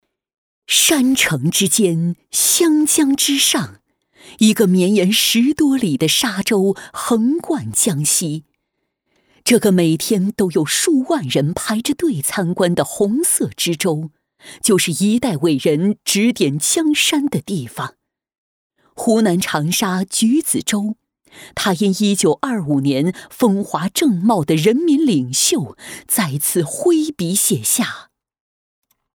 女33号